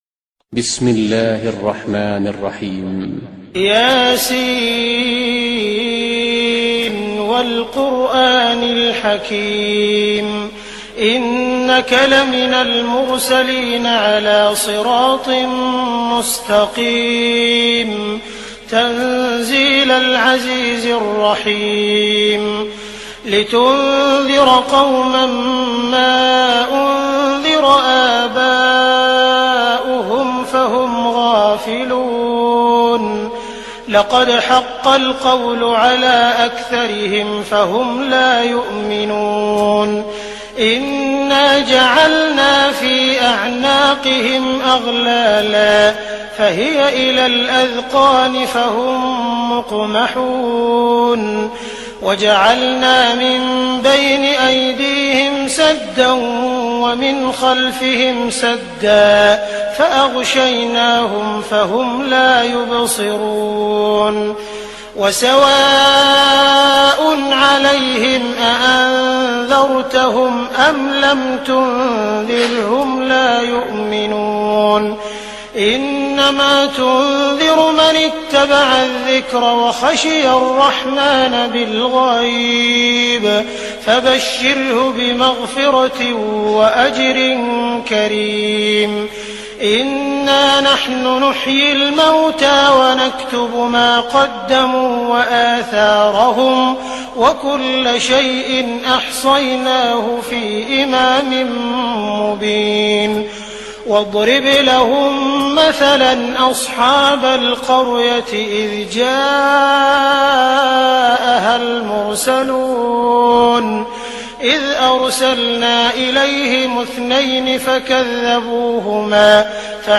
Listen to mesmerizing recitation of Surah Yaseen by Qari Abdul Basit, renowned his powerful voice, unique style & soulful delivery that inspires
Surah-Yaseen-full-with-beautiful-voice-Qari-Abdul-Basit-TubeRipper.com_.mp3